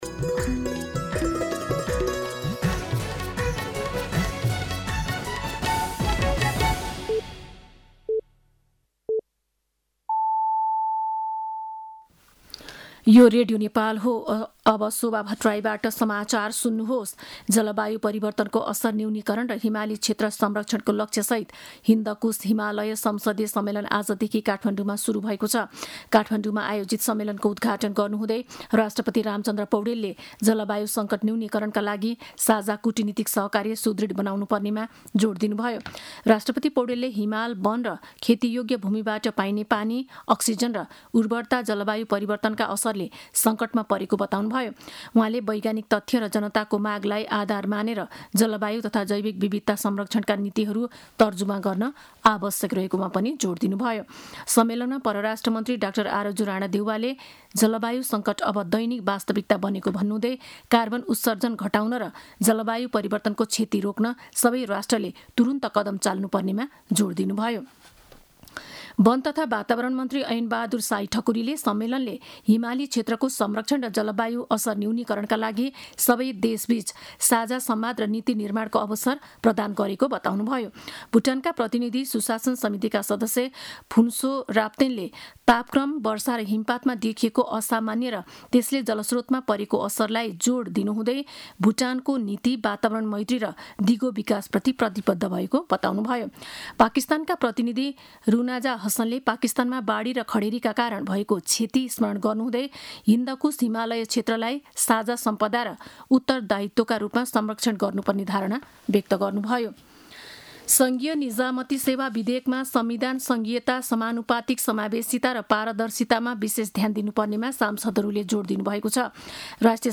साँझ ५ बजेको नेपाली समाचार : २ भदौ , २०८२
5.-pm-nepali-news-1-7.mp3